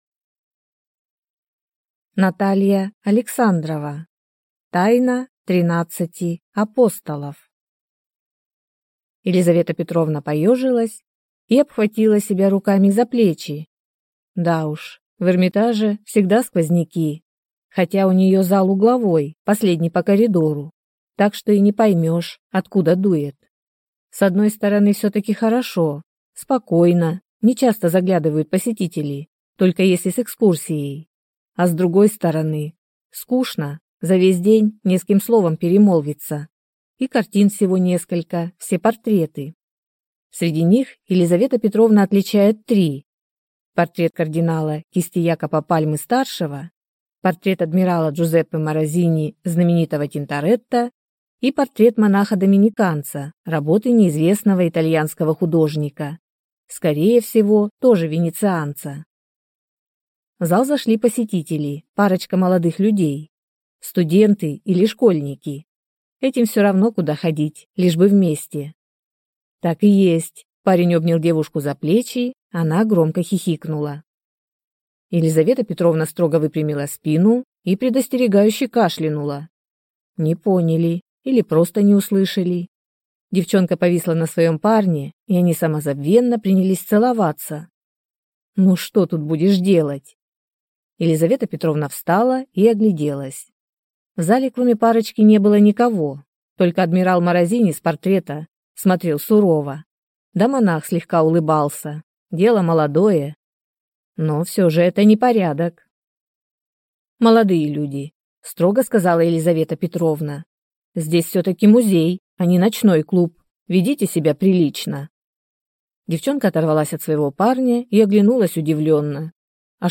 Аудиокнига Тайна тринадцати апостолов | Библиотека аудиокниг